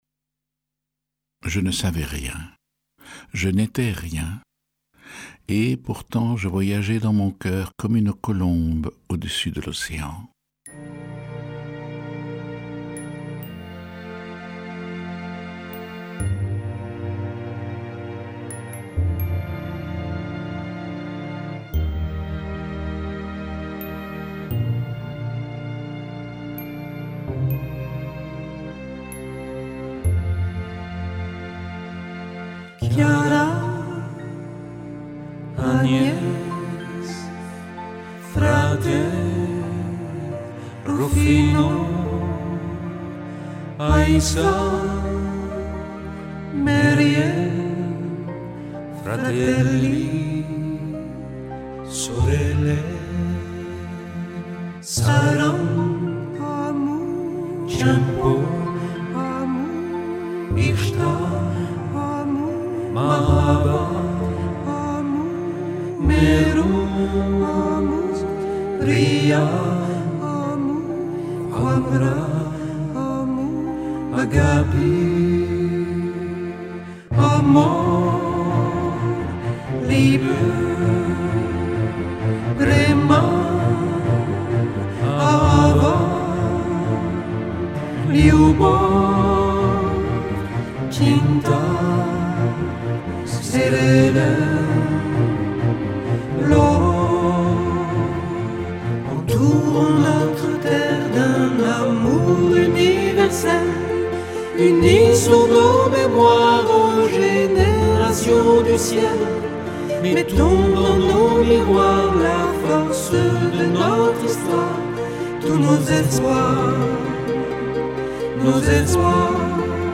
10-Arche-dalliance-chante.mp3